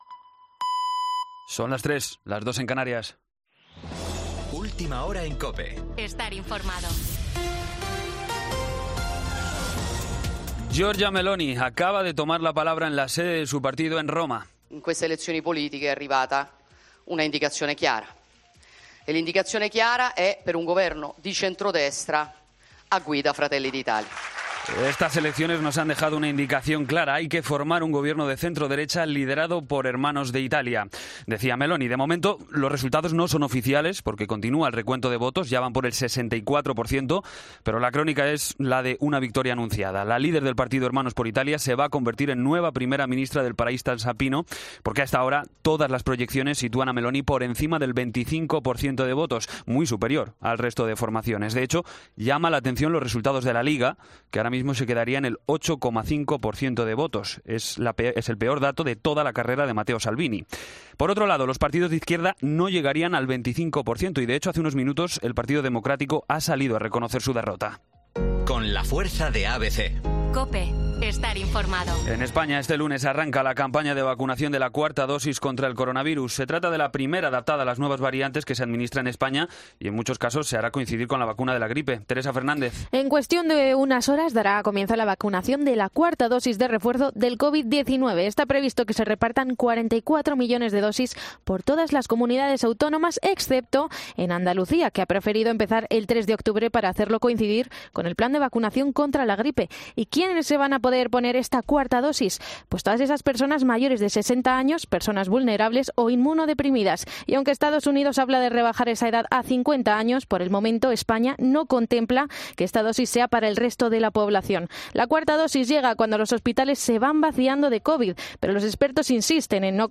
Boletín de noticias COPE del 26 de septiembre a las 03:00 hora
AUDIO: Actualización de noticias Herrera en COPE